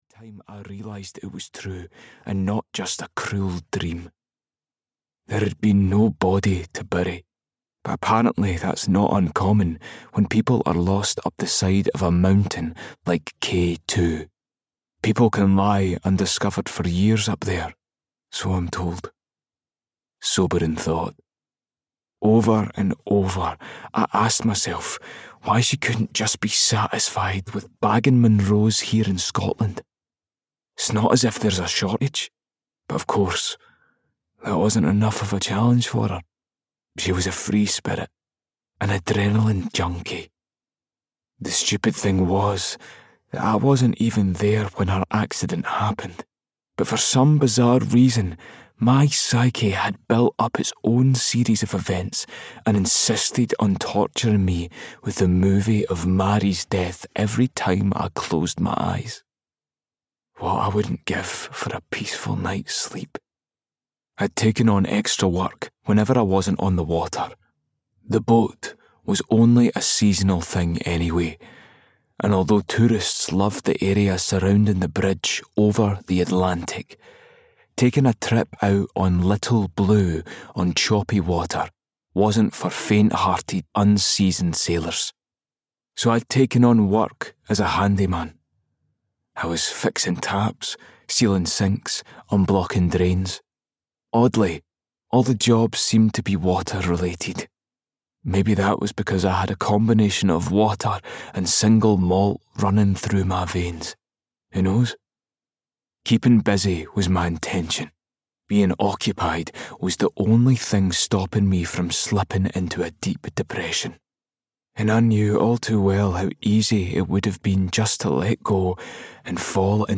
A Year of Finding Happiness (EN) audiokniha
Ukázka z knihy